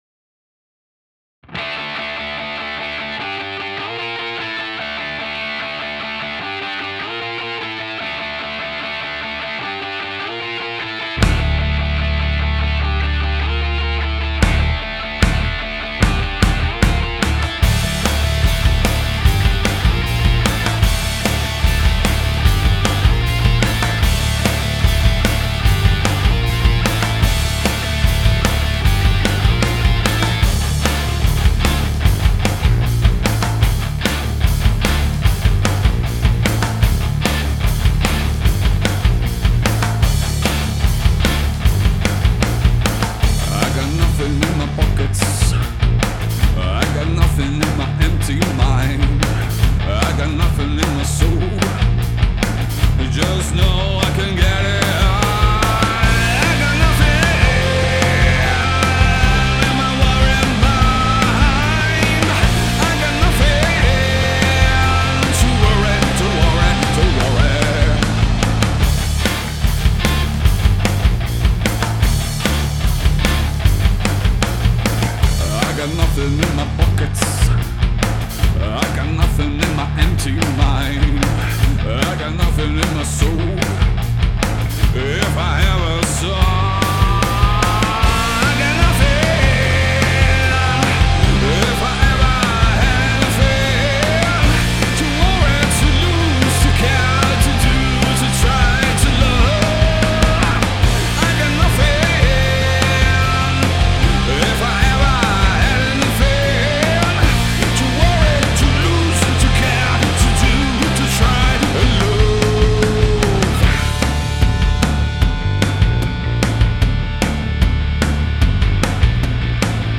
EstiloHeavy Metal